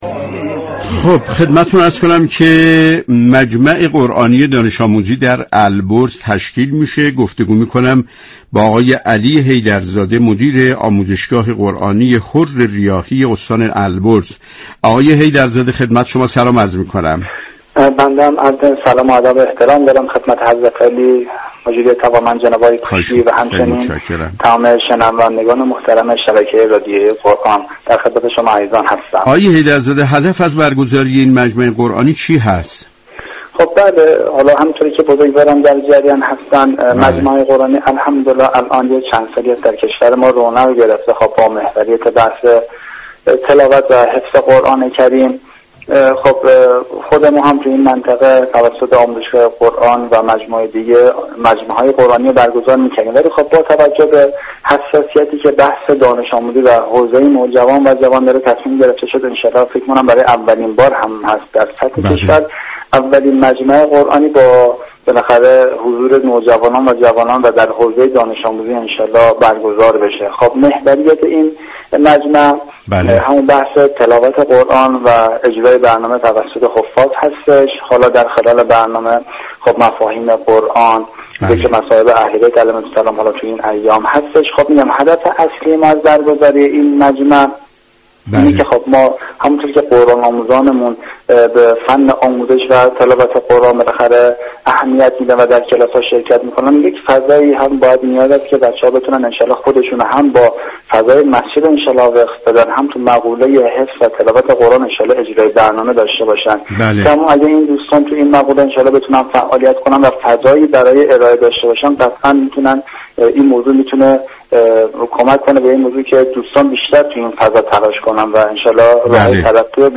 گفتنی است؛ جنگ عصرگاهی "والعصر" كه با رویكرد اطلاع رسانی یكشنبه تا چهارشنبه ی هر هفته بصورت زنده از رادیو قرآن پخش می شود.